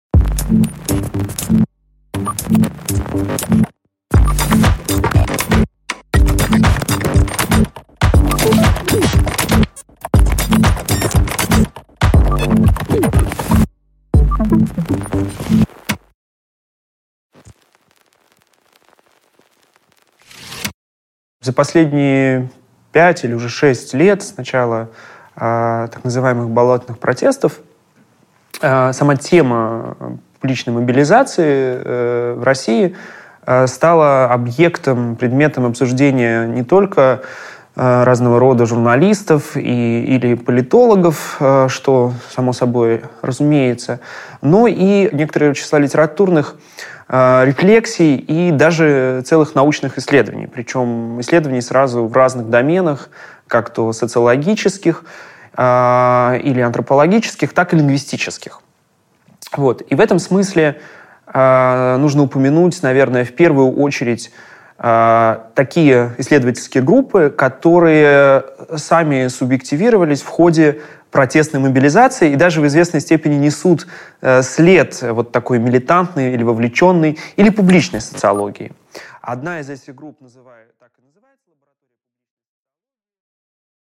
Аудиокнига Лингвистика протеста | Библиотека аудиокниг
Прослушать и бесплатно скачать фрагмент аудиокниги